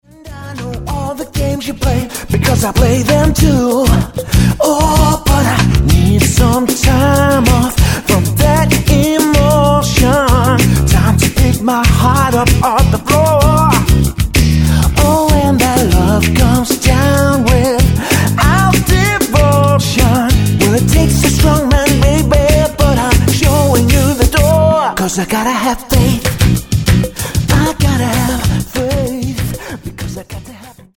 Tonart:B Multifile (kein Sofortdownload.
Die besten Playbacks Instrumentals und Karaoke Versionen .